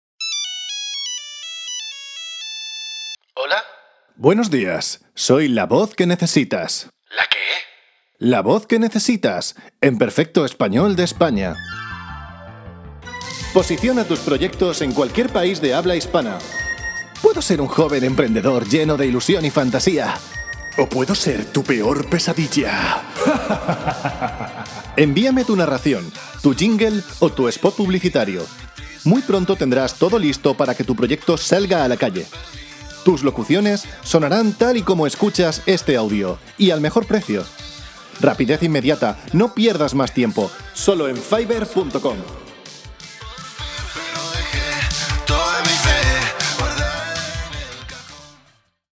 kastilisch
Sprechprobe: Industrie (Muttersprache):
Spanish singer and voice artist from Spain.